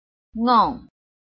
臺灣客語拼音學習網-客語聽讀拼-詔安腔-鼻尾韻
拼音查詢：【詔安腔】ngong ~請點選不同聲調拼音聽聽看!(例字漢字部分屬參考性質)